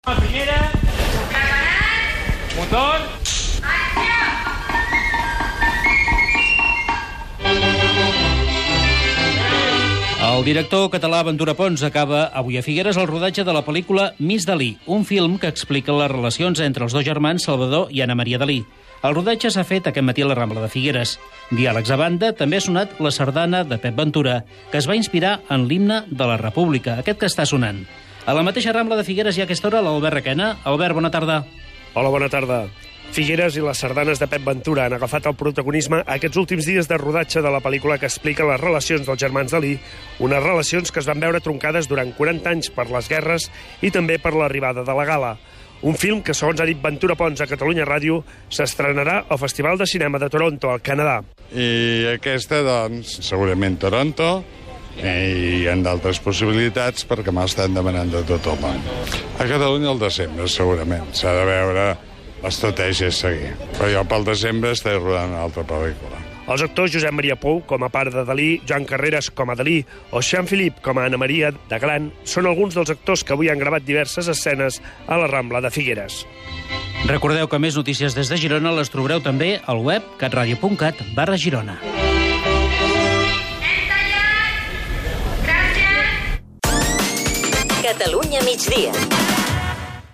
Fotografías y entrevistas del rodaje en Cadaqués y Figueres de la película Miss Dalí
Entrevistas realizadas para el programa de Televisió de Girona: "Torna-la a tocar, Sam",
finalitza_a_figueres_rodatge_miss_dali.mp3